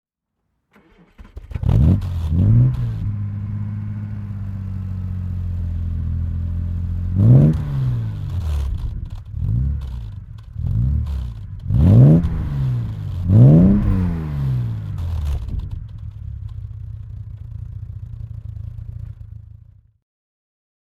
Lagonda Rapide (1963) - Starten und Leerlauf
Ton 1963